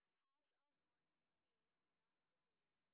sp14_street_snr0.wav